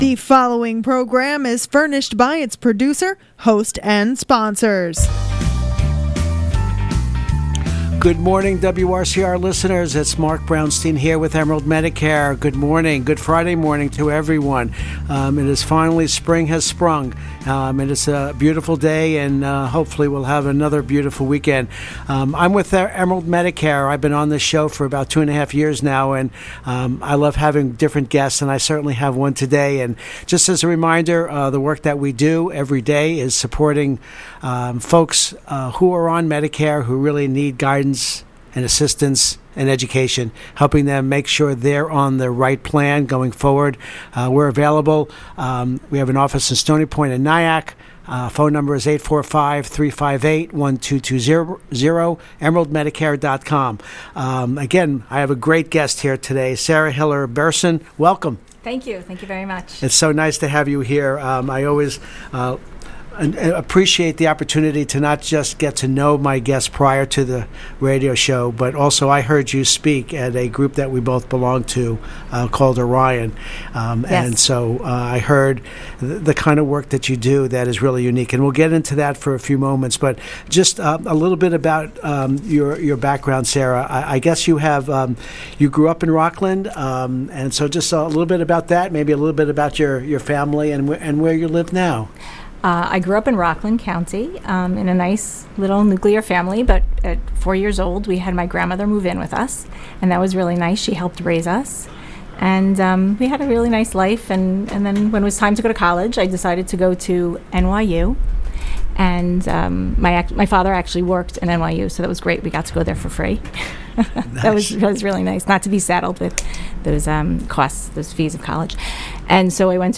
Listen To Our Radio Interview